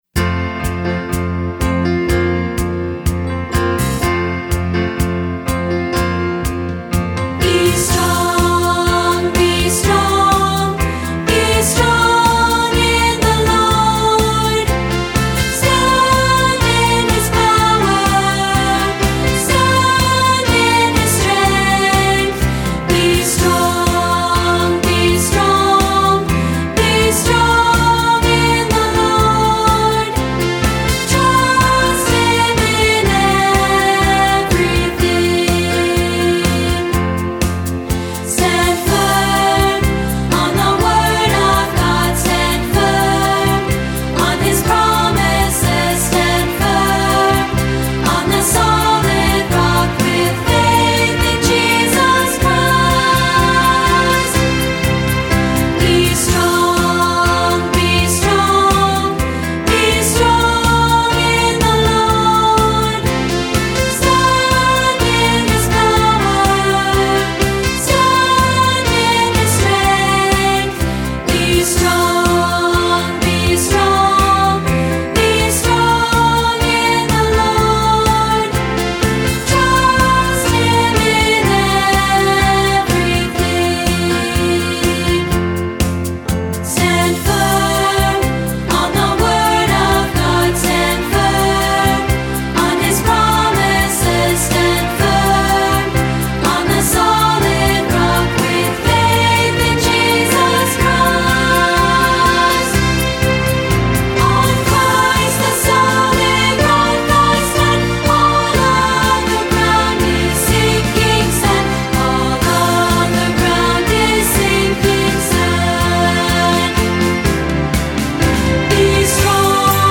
Voicing: Unison/2-Part